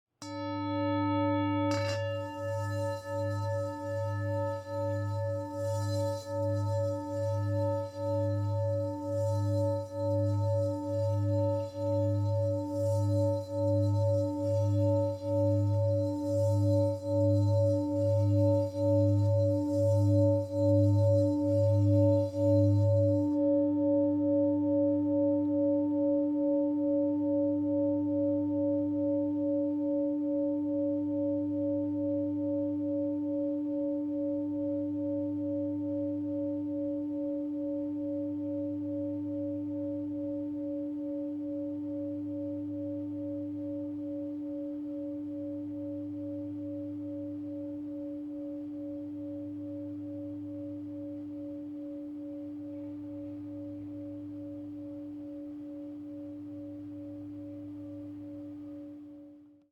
第1チャクラから第7チャクラ全てのチャクラをクリーニングできる音です。寝る前に、ゆったりとした気持ちで聴くといいです。